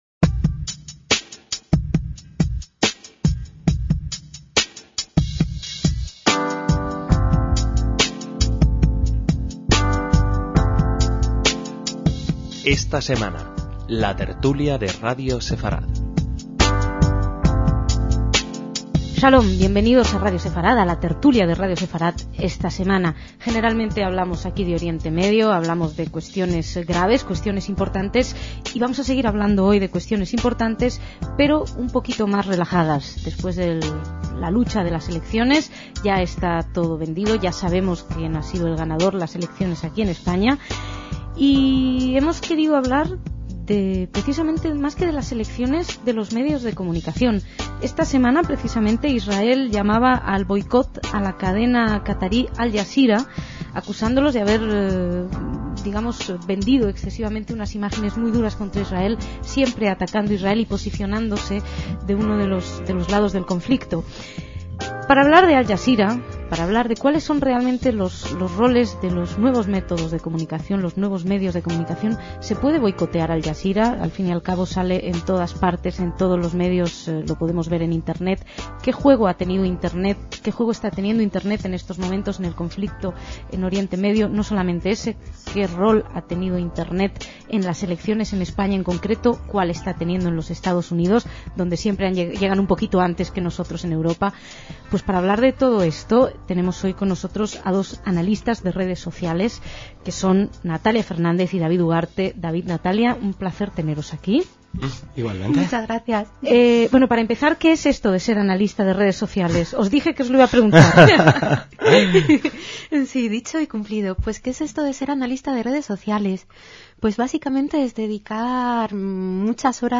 Para analizar el papel de las entonces emergentes redes sociales en Internet y el trasiego de información, participaron del debate